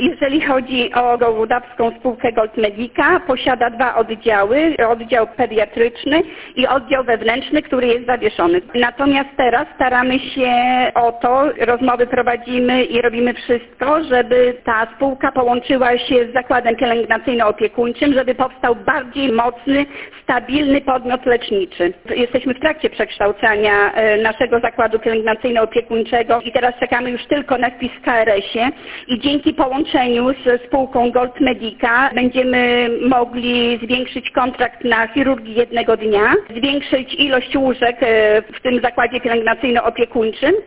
– Posiadamy bardzo dobre warunki, by ten oddział mógł prosperować na wysokim poziomie – zapewnia starosta.